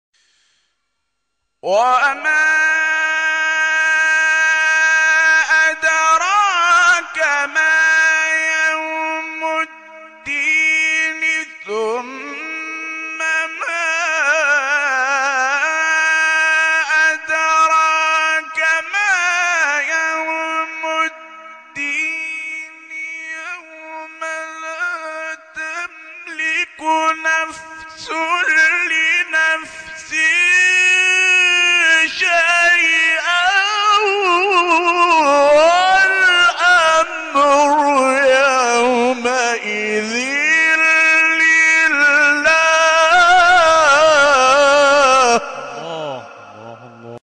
شبکه اجتماعی: فرازهای صوتی از تلاوت قاریان برجسته و ممتاز کشور را که به‌تازگی در شبکه‌های اجتماعی منتشر شده است، می‌شنوید.